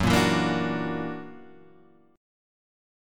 F# Augmented 7th